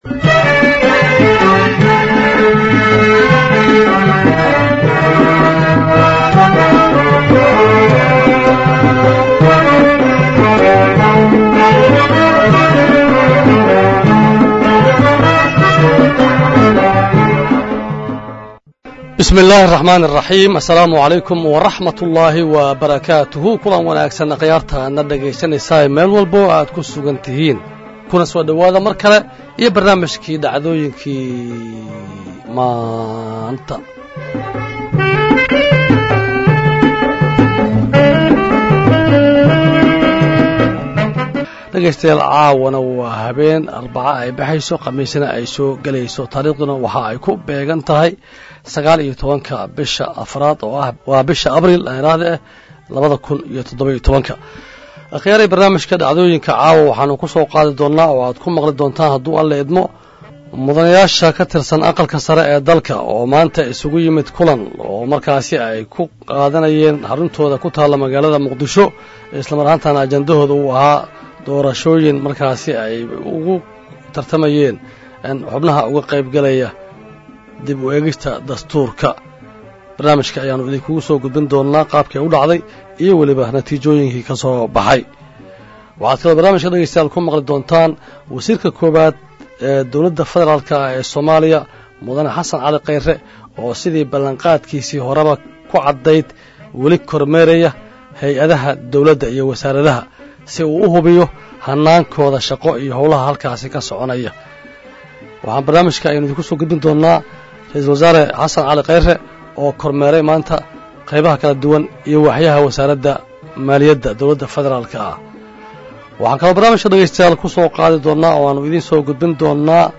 Barnaamijka Dhacdooyinka maanta oo ka mid ah barnaamijyada maalin-laha ah ee ka baxa Radio Muqdisho, 09:30 illaa 10:30 habeenimo, ayaan idin kugu soo gudbinnaa wareysiyo xiisa badan oo ka hadlaaya Siyaasadda Soomaaliya iyo arrimaha bulshada waxyaabaha ugu xiisa badan.